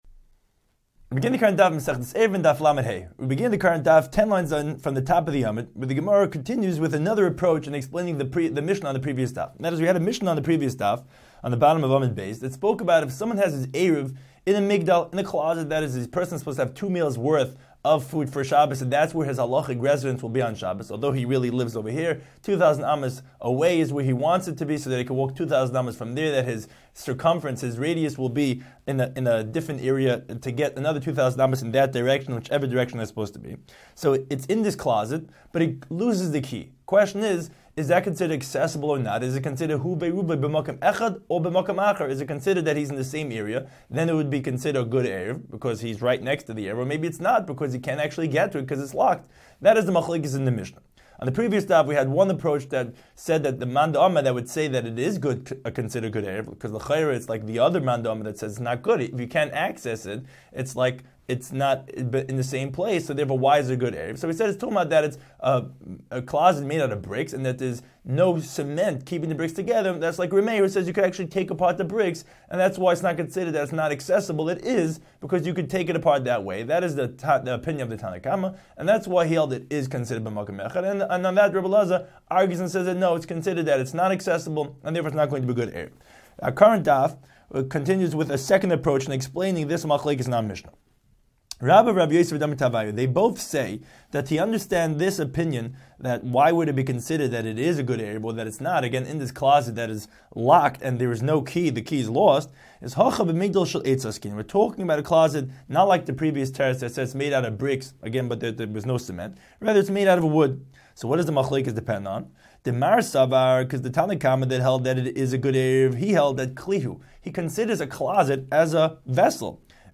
Daf Hachaim Shiur for Eruvin 35